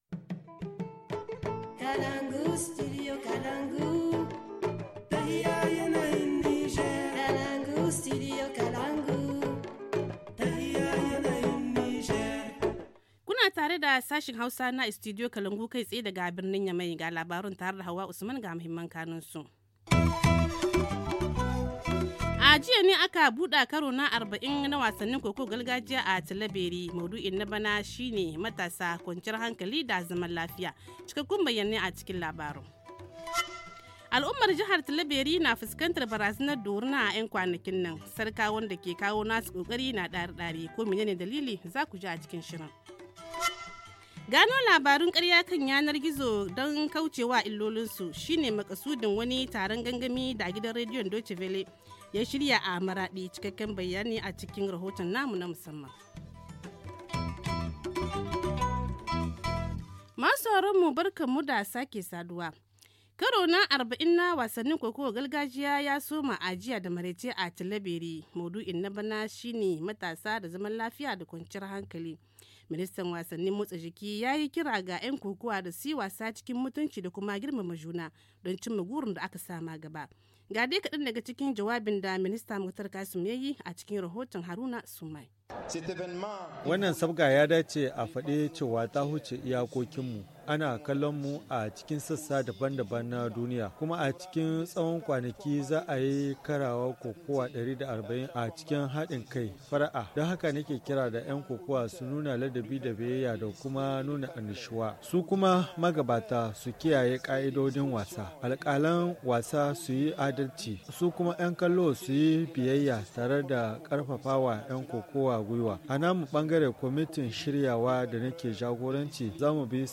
Le journal du 26 décembre 2018 - Studio Kalangou - Au rythme du Niger